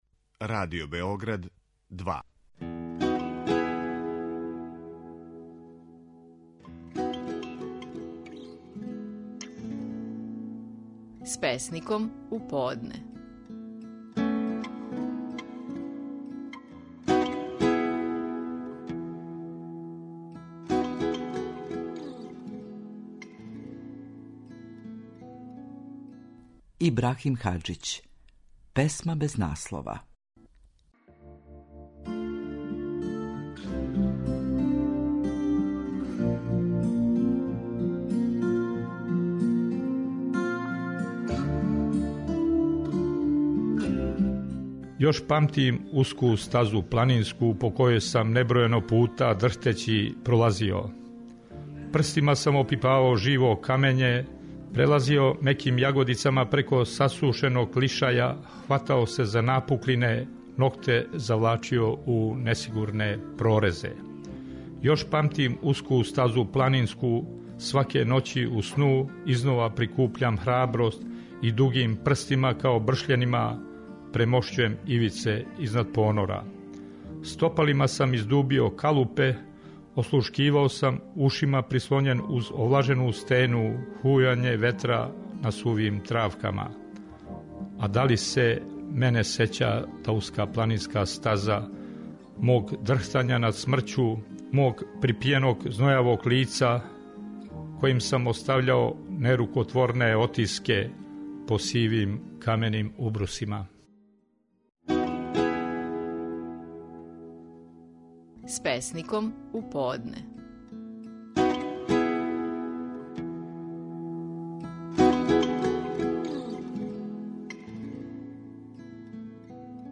Стихови наших најпознатијих песника, у интерпретацији аутора